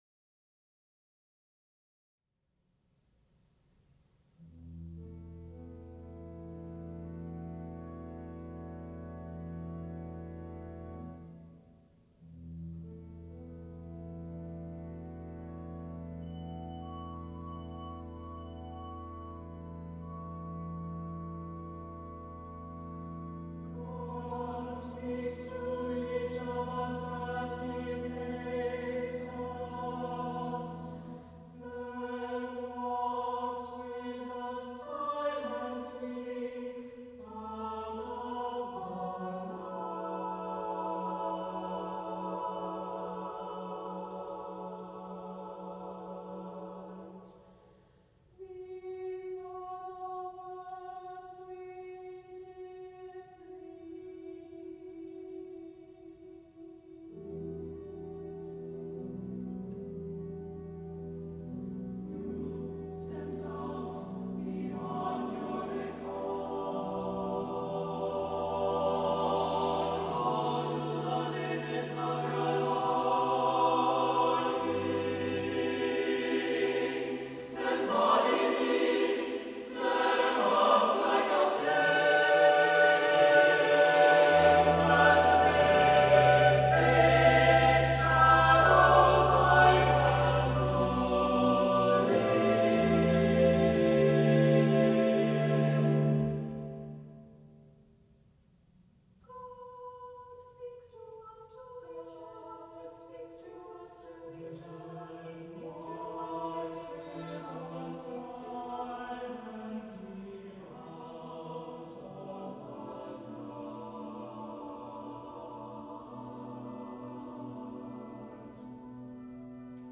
for SATB Chorus and Organ (1999)